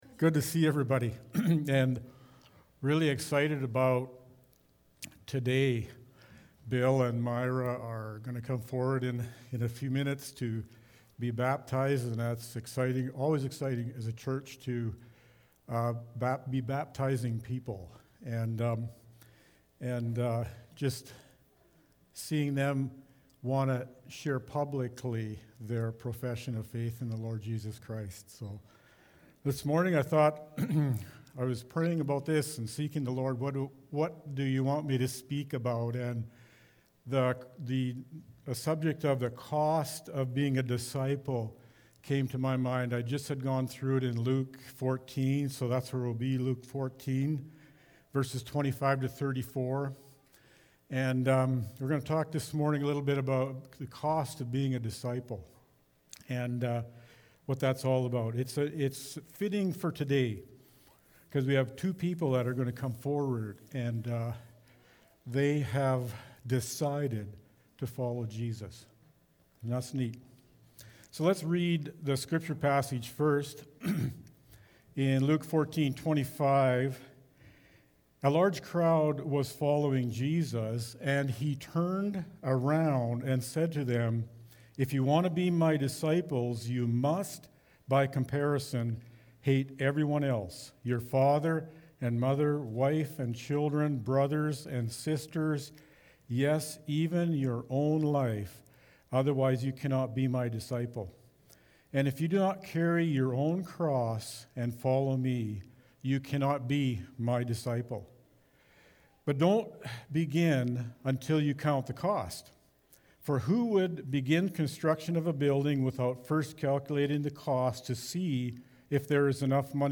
Feb-7-sermon.mp3